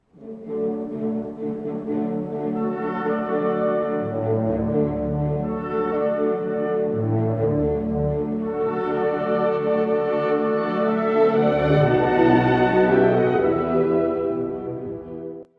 全体としては、がっしりした西洋音楽らしい曲です。
↑古い録音のため聴きづらいかもしれません！（以下同様）
その通り、晴れやかな気持ちで歩きたくなるような楽想です。